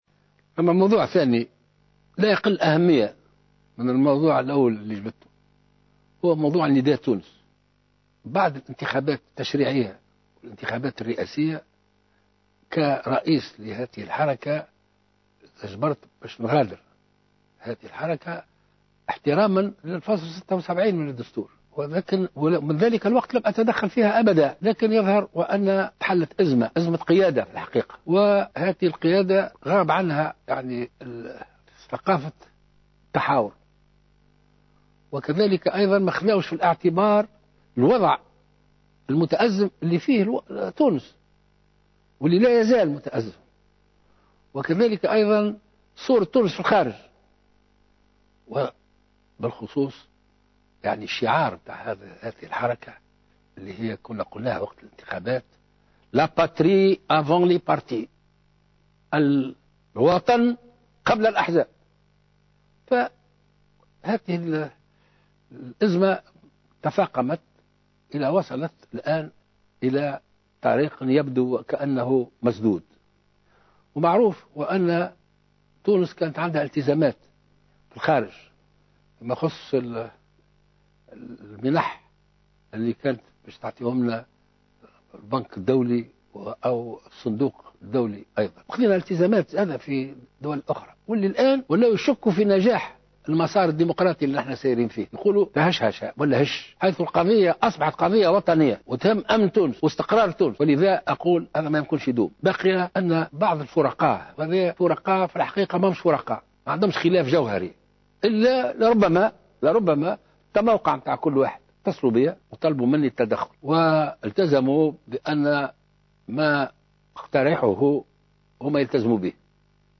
Le chef de l’Etat a présenté au cours d’une allocution diffusée dimanche soir sur Al Watania son initiative pour mettre fin à cette crise.